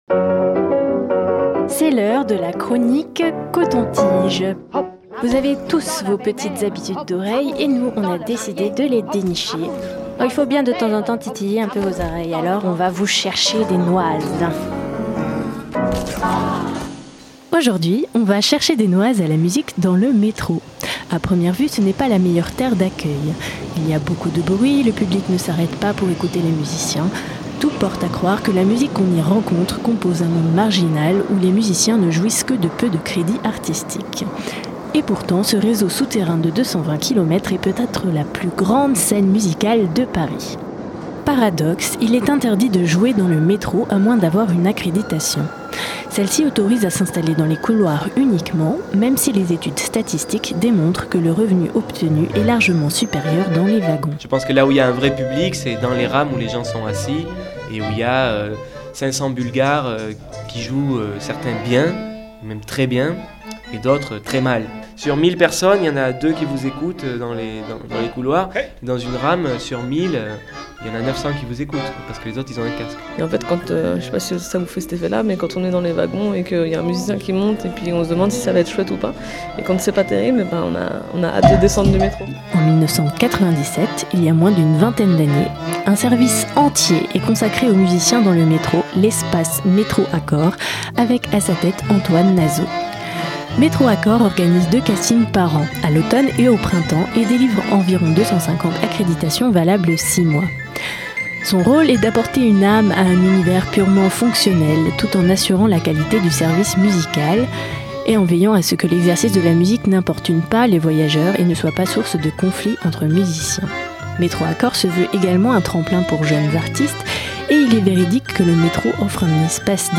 C'est en réalité un trafic très régulé ! Entre plaisir et énervement, on est allé chercher des Noises à la musique dans le métro, en passant par les auditions du service "Métro Accords" de la RATP.